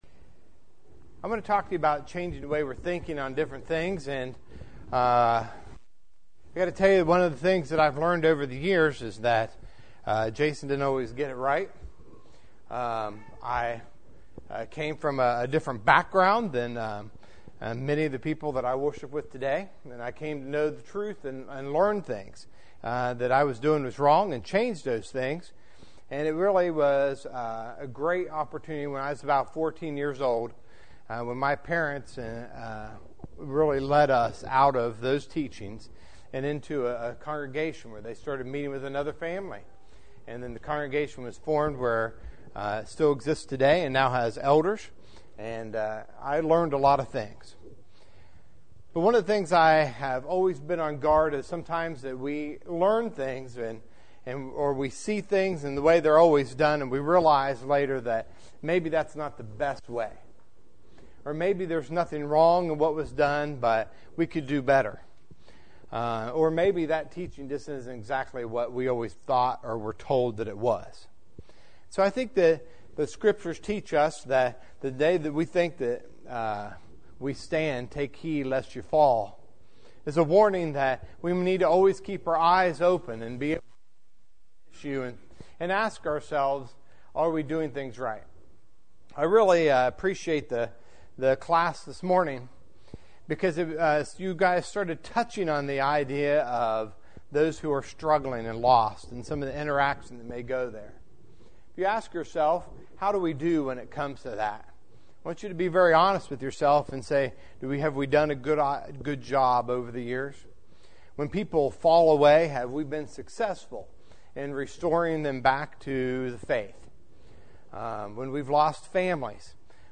The first lesson was an expository lesson from the book of Mark, chapter five.